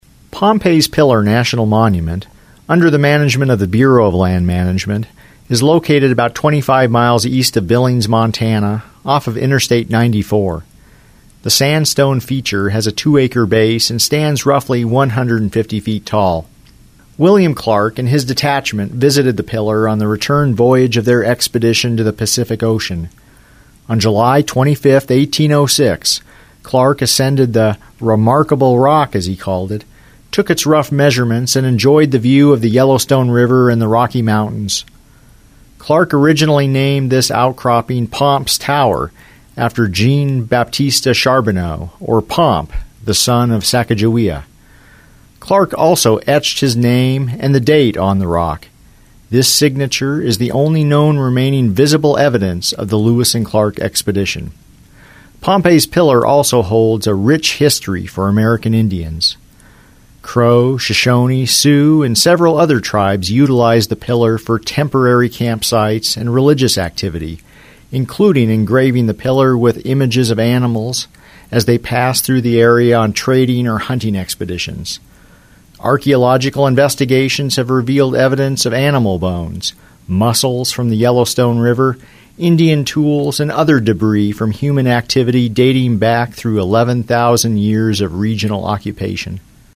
Researched, written, and narrated by University of West Florida Public History Student